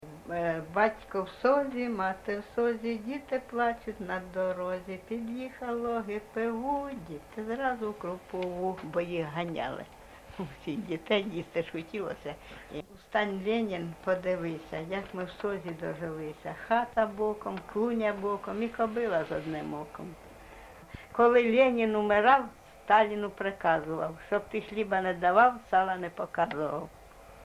ЖанрСучасні пісні та новотвори, Частівки
Місце записус-ще Ясна Поляна, Краматорський район, Донецька обл., Україна, Слобожанщина